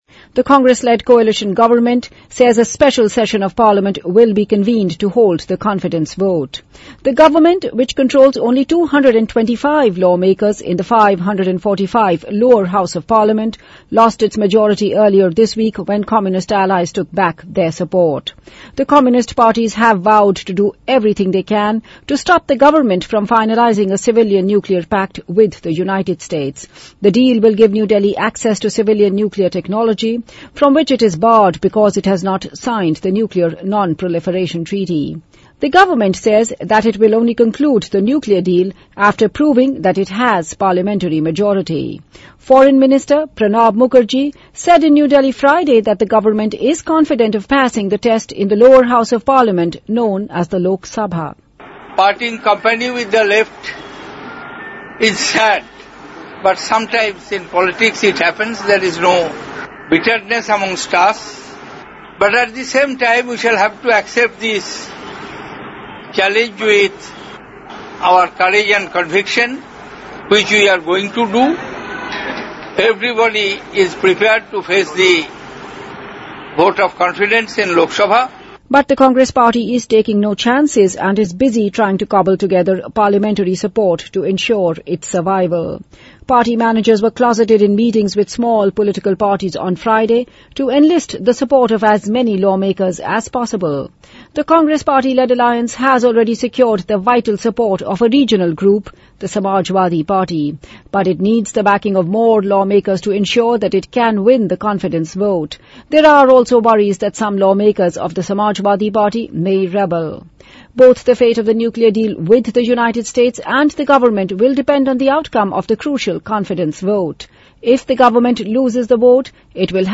位置：首页 > 英语听力 > 英语听力教程 > 英语新闻听力